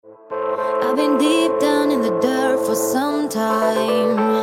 • Качество: 256, Stereo
поп
женский вокал
спокойные
Melodic